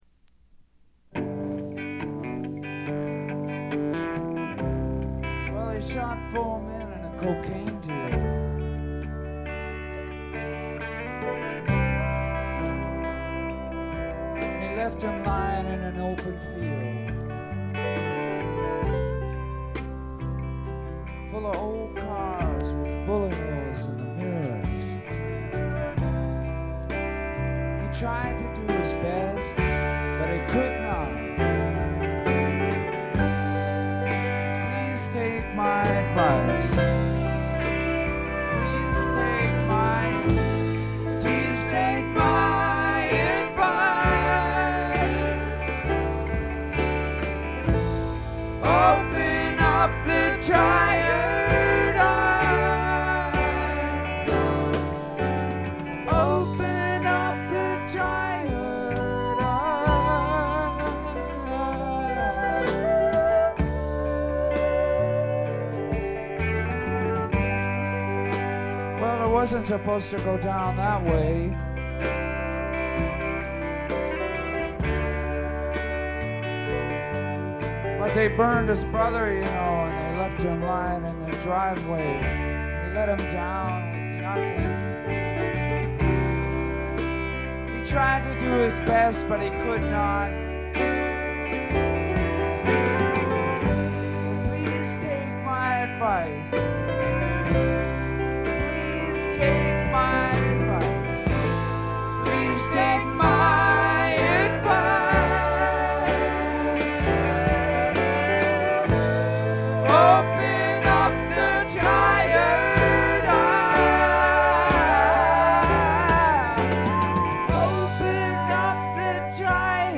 特に目立つノイズはありません。
試聴曲は現品からの取り込み音源です。
steel guitar